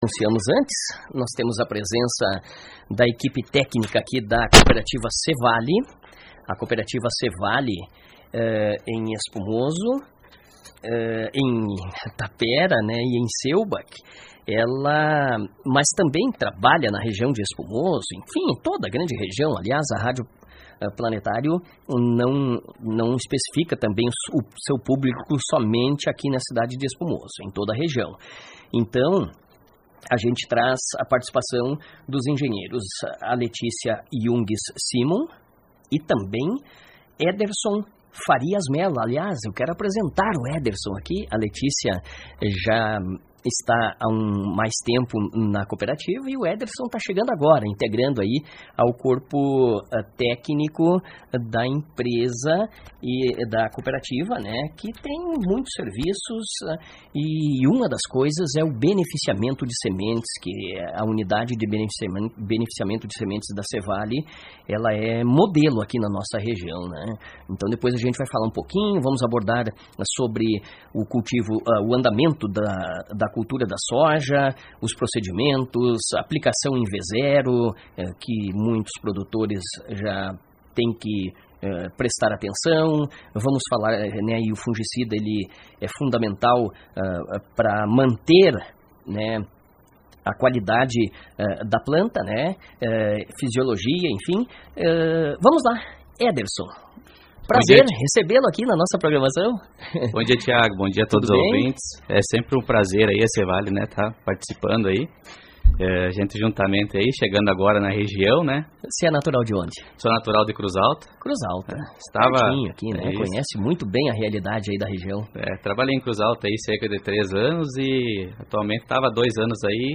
Confira a seguir a entrevista que realizamos com a equipe técnica da C.Vale